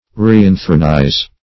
Reinthronize \Re`in*thron"ize\ (-?z)
reinthronize.mp3